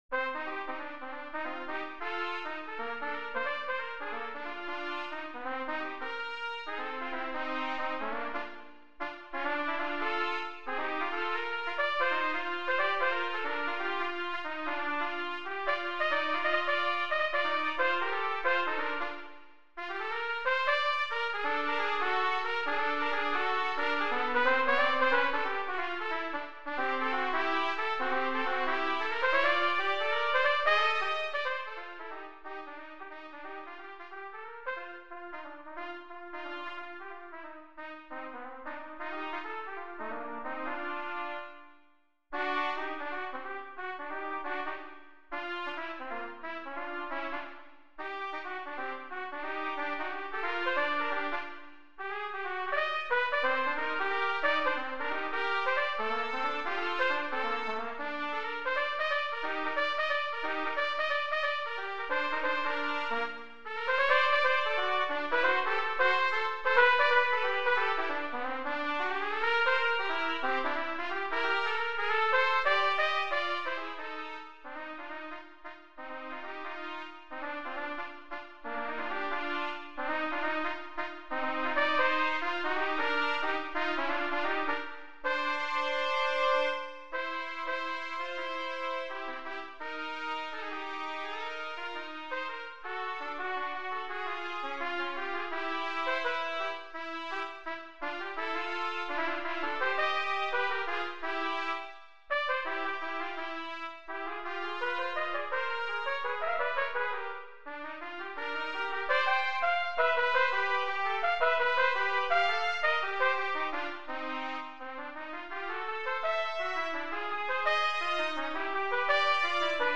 Gattung: Für 2 Trompeten